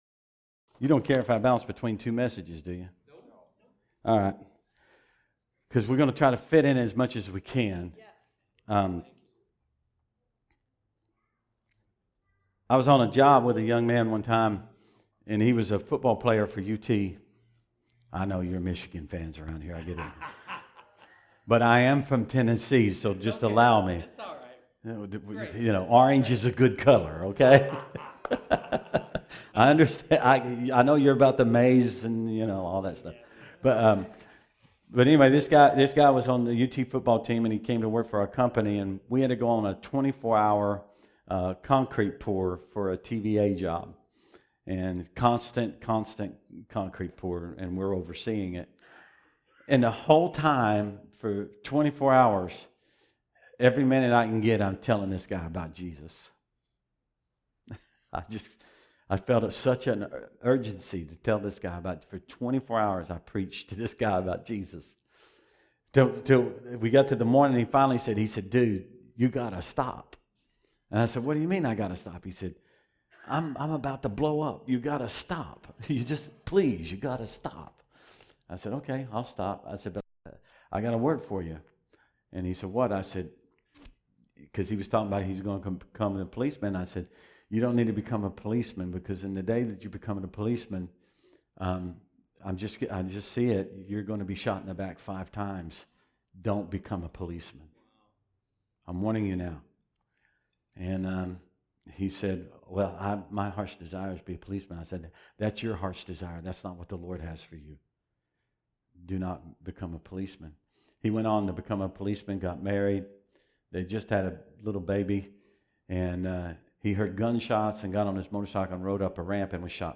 Message 2 Sermon
Guest Speaker